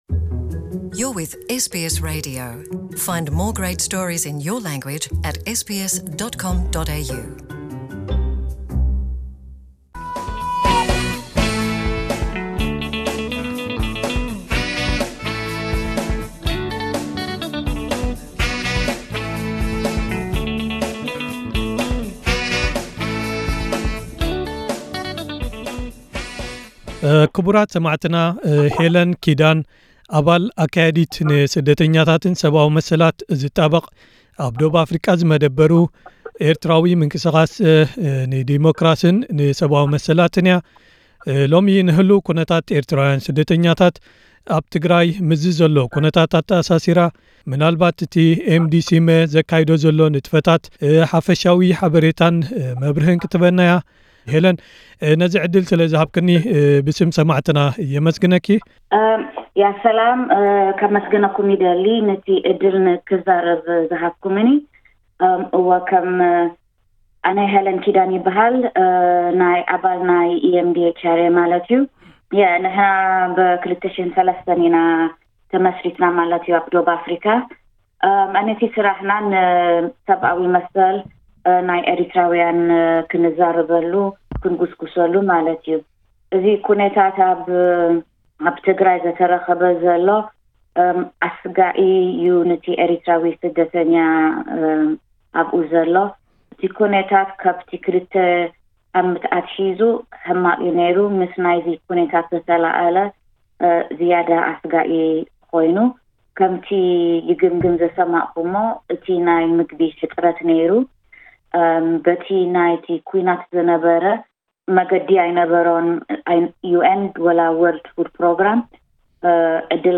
ንኩነታት እዚ ጎስጓሳትን ናይ ስደተኛታት ኣብ መዓስከራት ትግራይ ንምሕጋዝ ዝካየድ ዘሎ ጻዕርታትን ዝምልከት ምስ ኤስቢኤስ ትግርኛ ቃለመሕትት ኣካይዳ ኣላ።